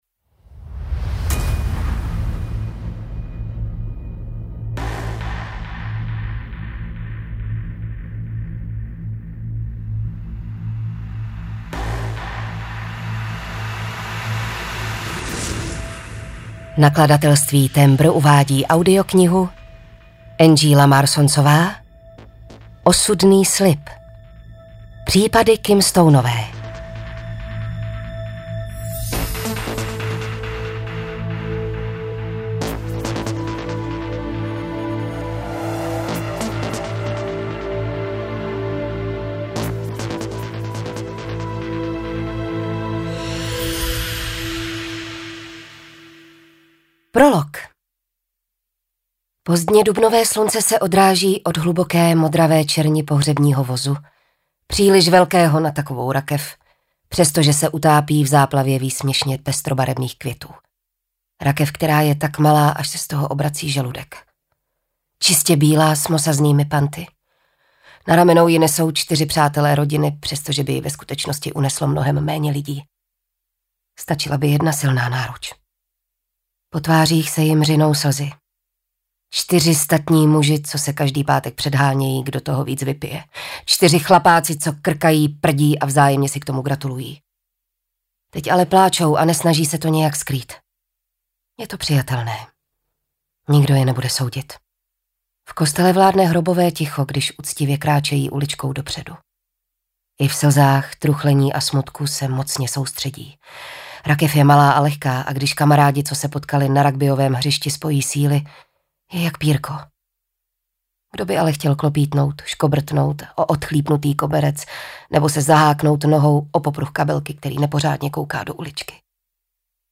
Audiokniha Osudný slib, kterou napsala Angela Marsonsová.
Ukázka z knihy